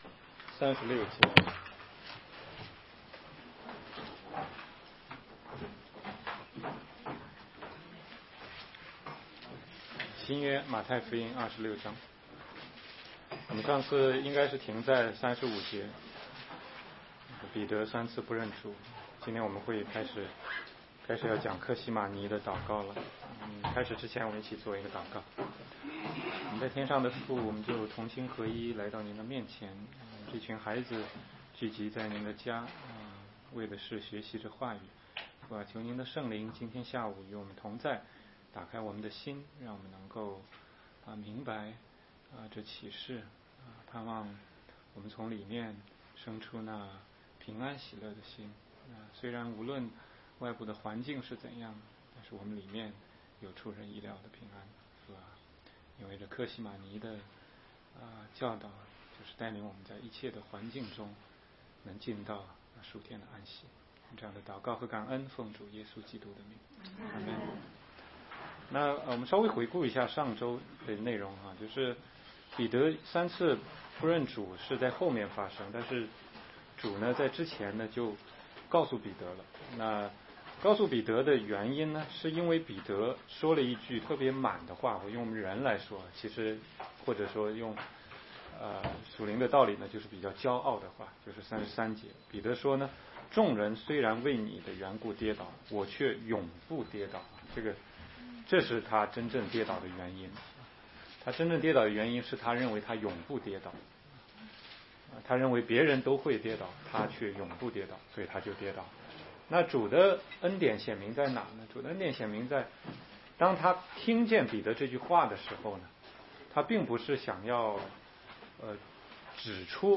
16街讲道录音 - 马太福音26章36-40节：客西马尼